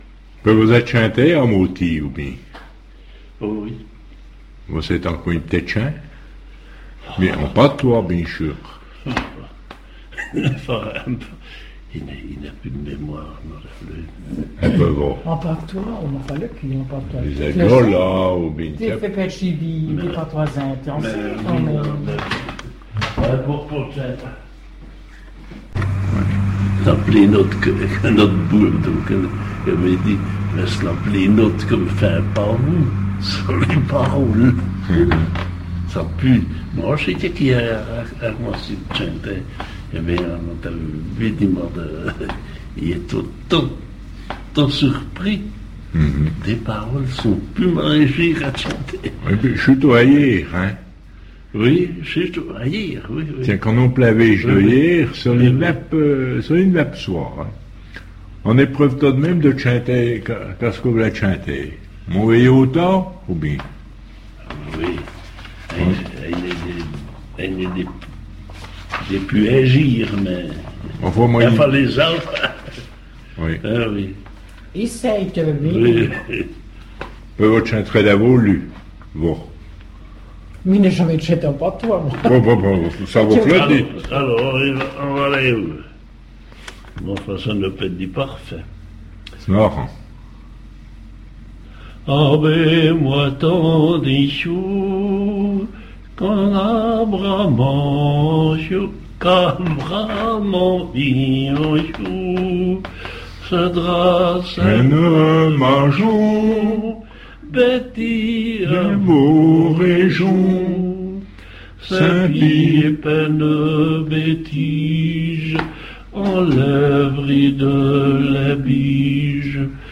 Chanson : mon veye hôta